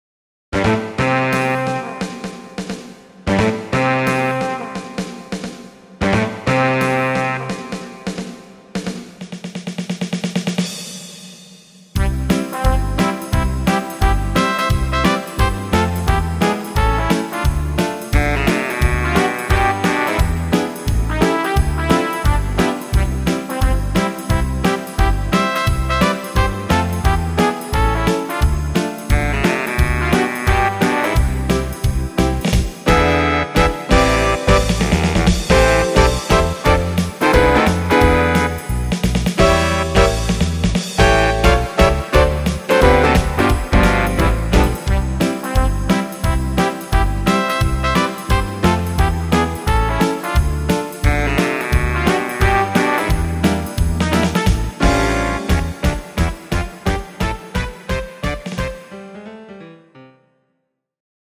Trompete